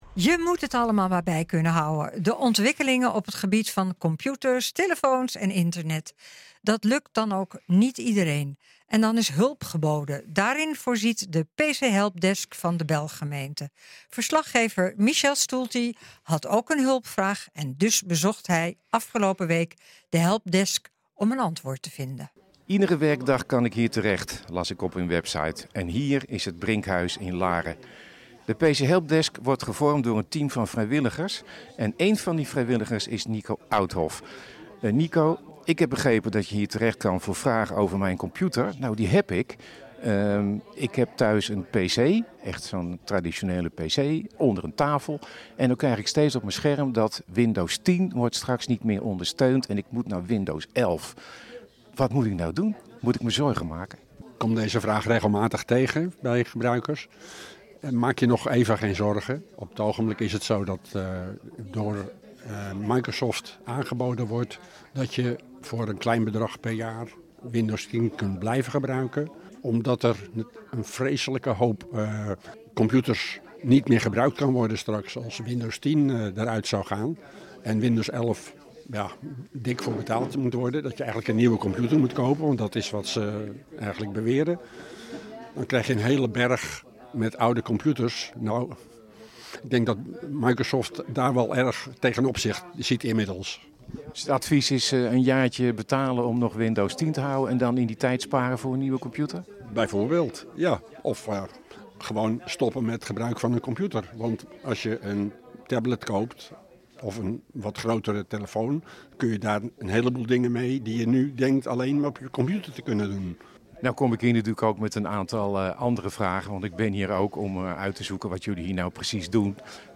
Elke werkdag tussen 10.00 en 12.00 uur kunnen inwoners terecht in het Brinkhuis voor hulp met allerlei vragen over hun computerapparatuur.